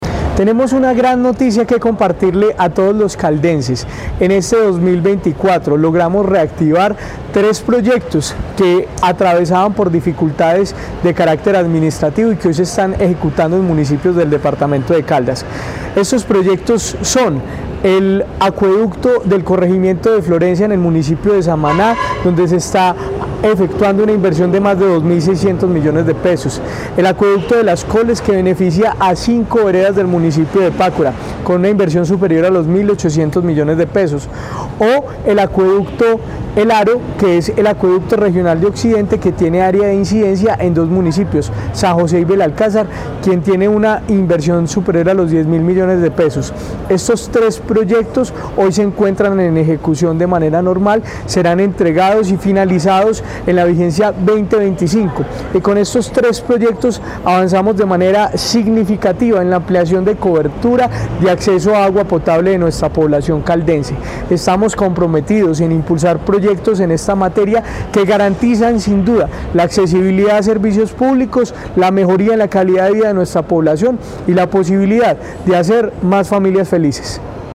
Jorge William Ruiz Ospina, secretario de Vivienda de Caldas.
Jorge-William-Ruiz-Ospina-secretario-de-Vivienda-de-Caldas-2.mp3